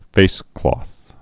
(fāsklôth, -klŏth)